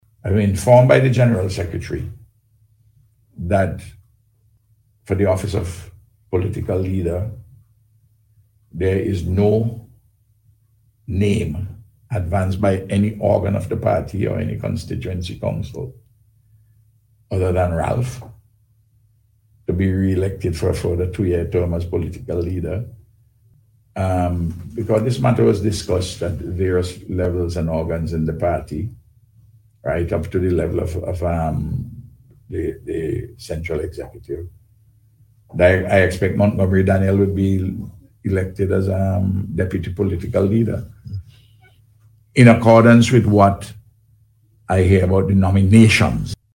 The Prime Minister made that announcement on Radio yesterday.